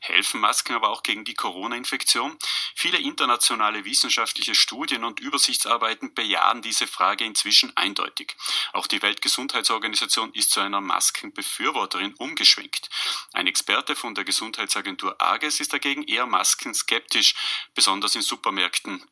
Wer ist gegen eine Maskenpflicht zur Bekämpfung der Corona-Infektion? Hören Sie die Nachrichtensendung an.